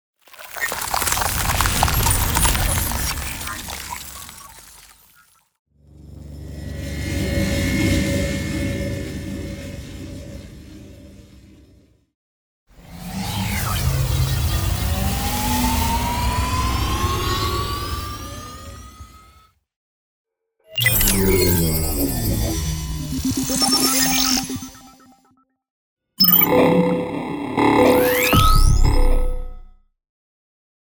游戏音效[科幻类] – 深圳声之浪潮文化传播有限公司
【科幻类】是科幻音效，作为声音设计中的重要类别，广泛应用于描绘未来科技与高能场景。无论是太空飞船的引擎轰鸣、激光武器的能量充能，还是机器人语音、智能设备的电子提示音，乃至星际战舰跃迁时的空间扭曲声、能量护盾启动的低频嗡鸣，均属于科幻音效的范畴。